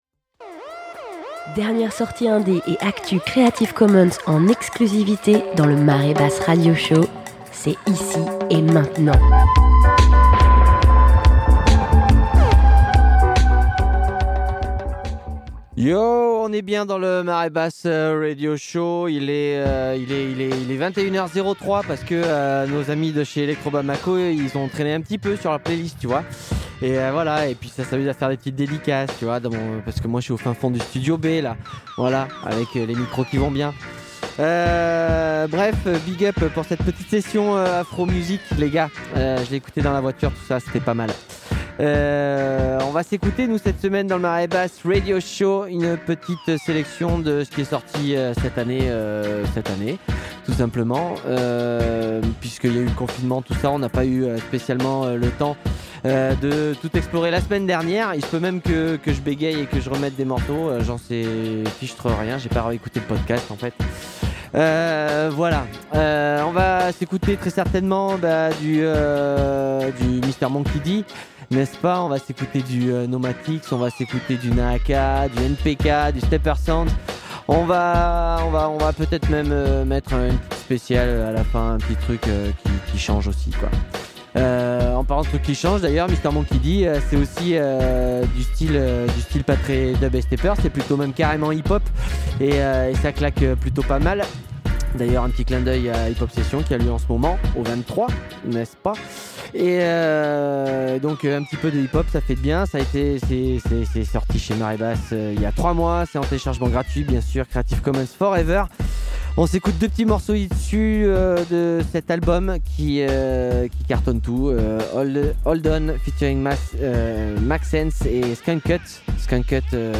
Reggae-Dub